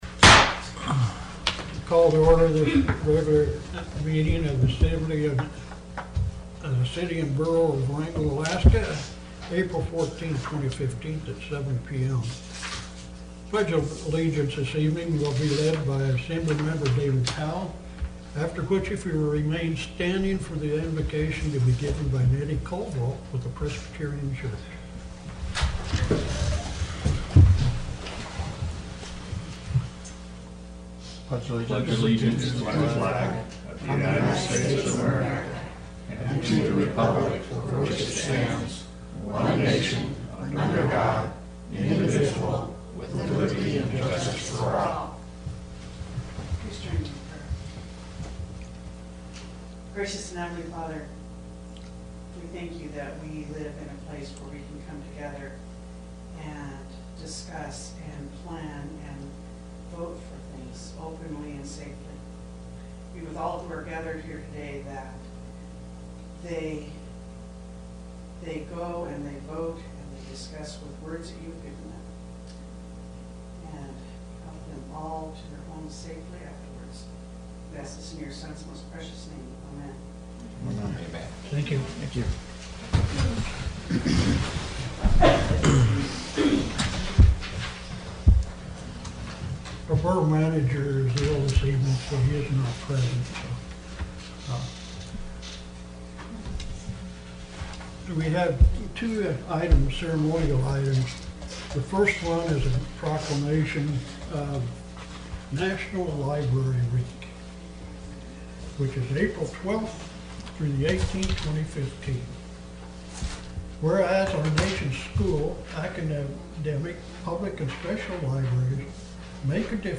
Wrangell's Borough Assembly held its regular meeting Tuesday, April 14 in the Assembly Chambers.
City and Borough of Wrangell Borough Assembly Meeting AGENDA April 14, 2015 – 7:00 p.m. Location: Assembly Chambers, City Hall